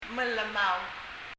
mellemau[mɛllɛməu]blue